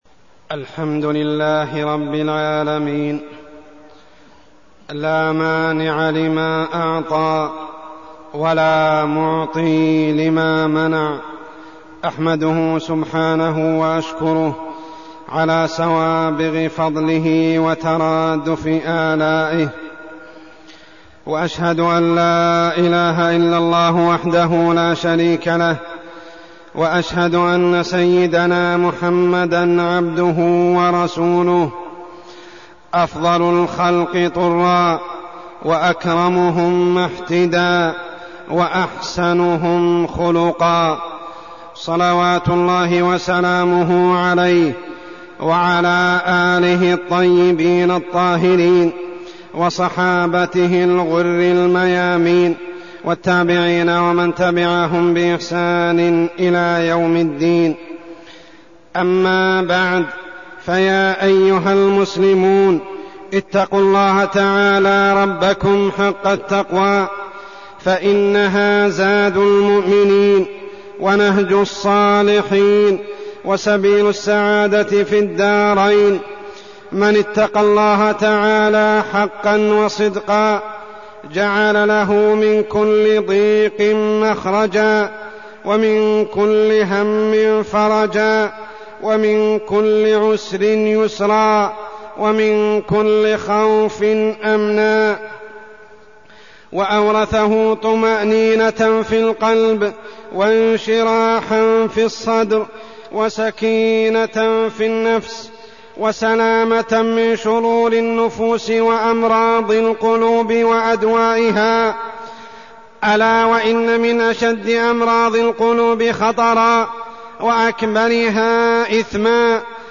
تاريخ النشر ٢٧ جمادى الآخرة ١٤١٧ هـ المكان: المسجد الحرام الشيخ: عمر السبيل عمر السبيل داء الحسد